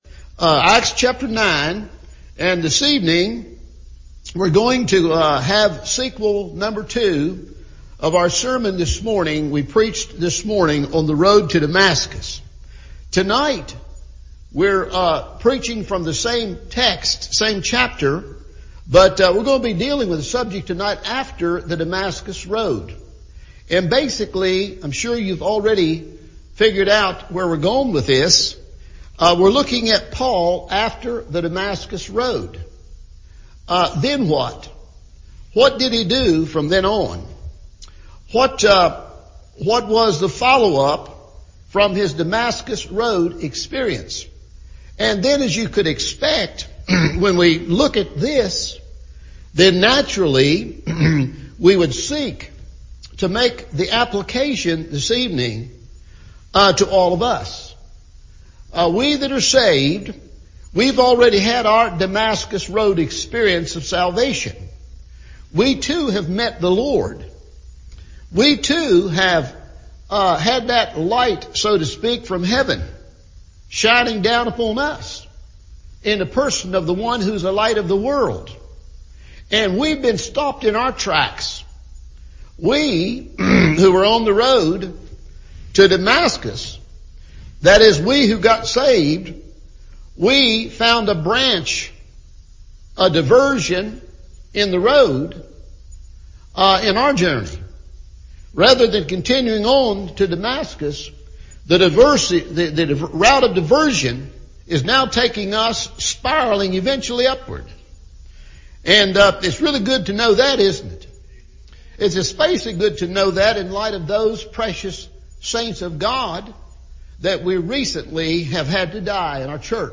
After the Damascus Road – Evening Service